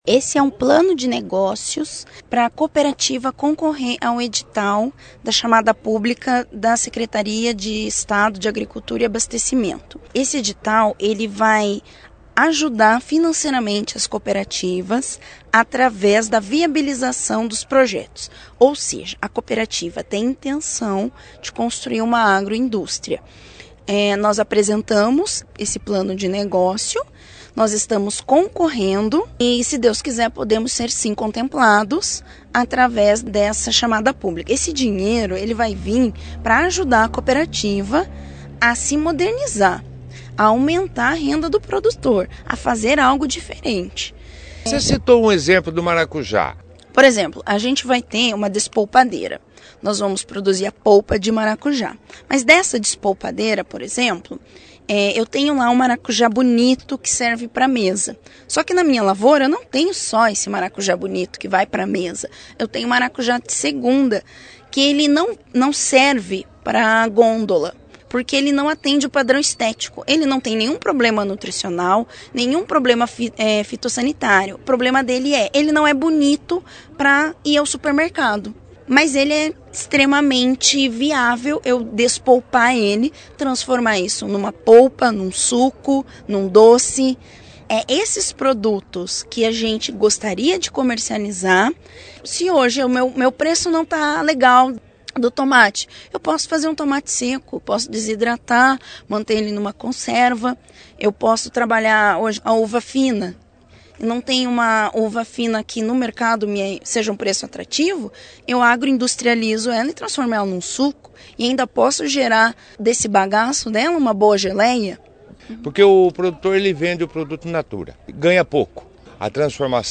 A assembleia foi destaque da 1ª edição do jornal Operação Cidade desta quarta-feira